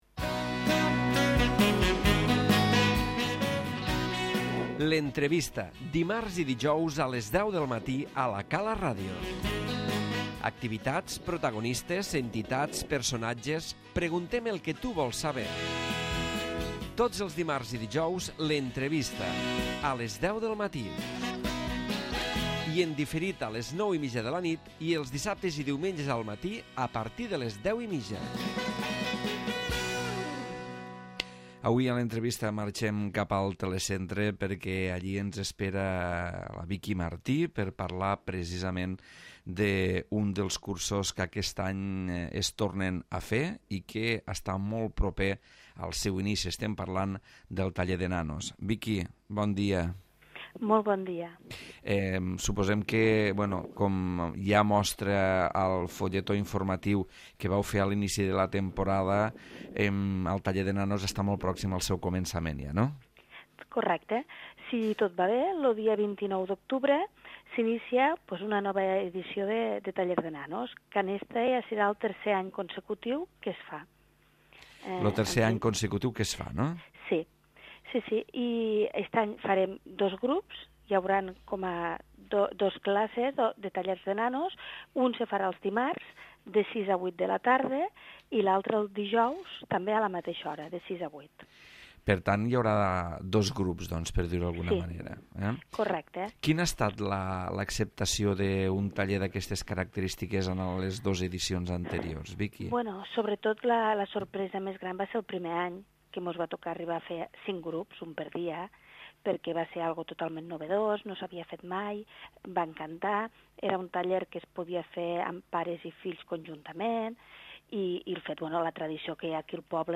L'Entrevista
Viqui Martí, des de la regidoria de Formació, ens parla a l'espai de l'Entrevista del taller de nanos. Taller que comença el 29 d'aquest mes d'octubre i serà el tercer any que es realitza.